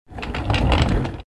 Звуки стула
Звук передвижения стула на колесиках по полу